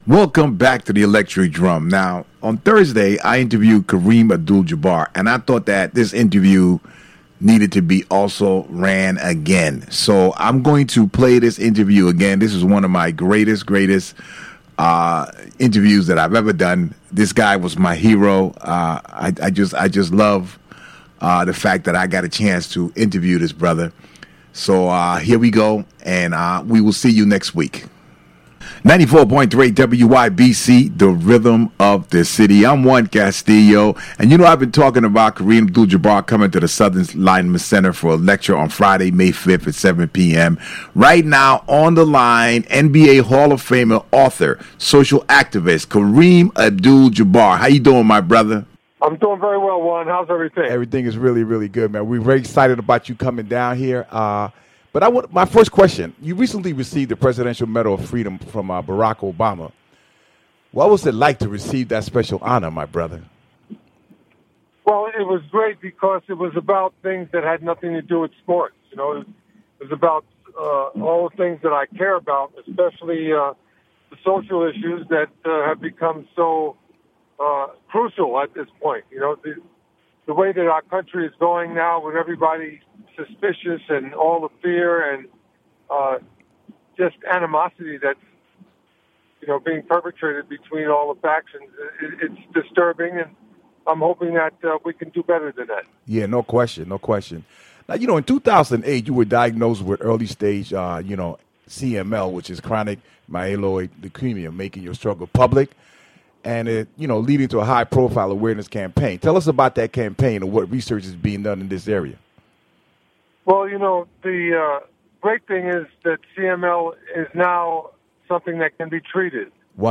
The interview originally aired on the PM Workforce earlier in the week.